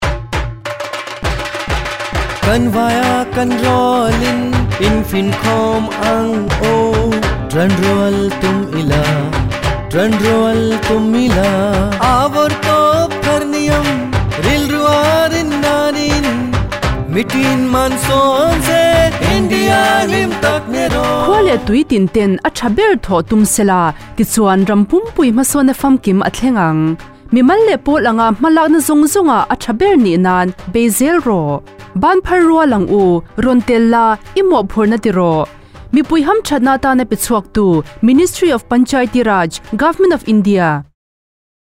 166 Fundamental Duty 10th Fundamental Duty Strive for excellence Radio Jingle Mizo